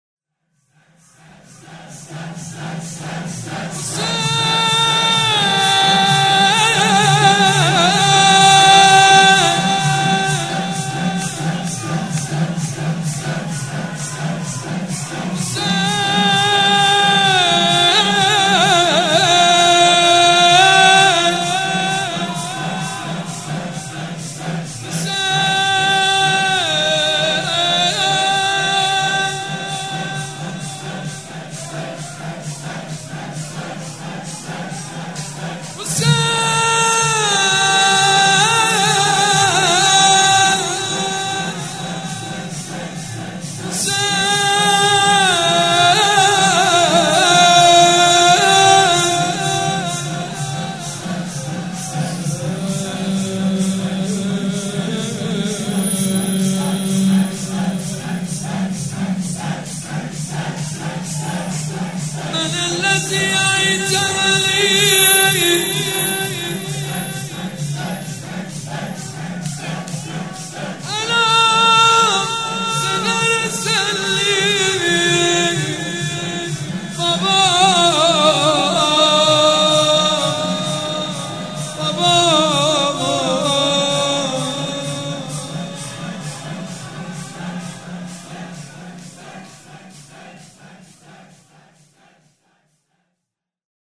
سبک اثــر شور مداح حاج سید مجید بنی فاطمه
مراسم عزاداری شب سوم